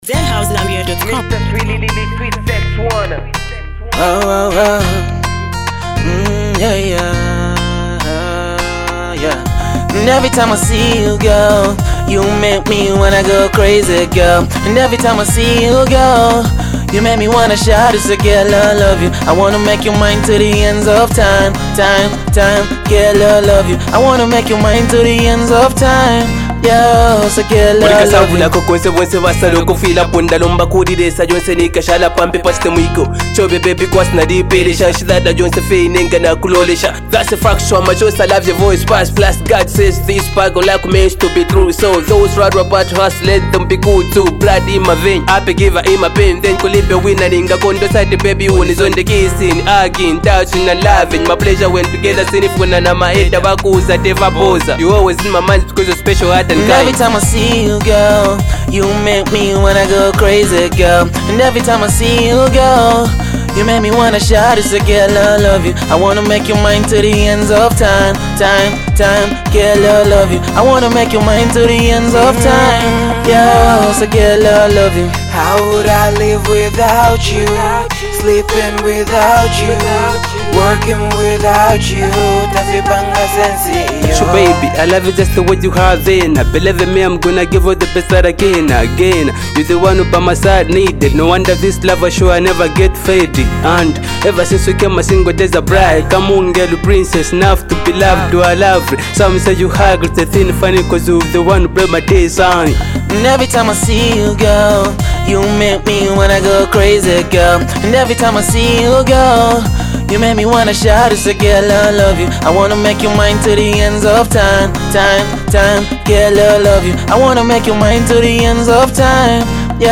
soulful collaboration